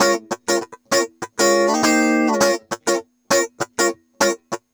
100FUNKY02-L.wav